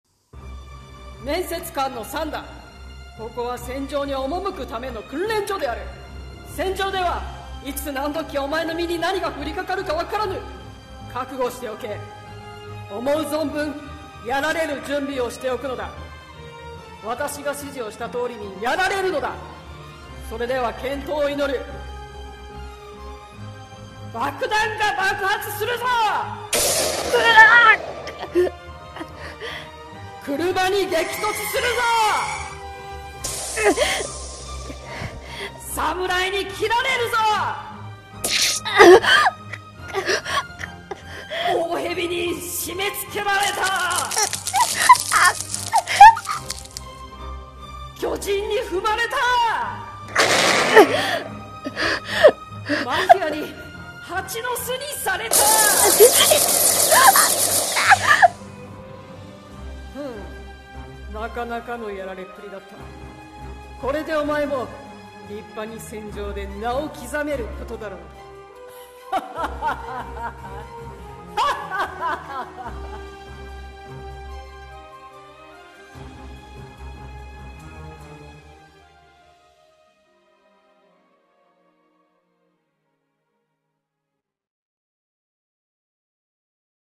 「やられ役」声面接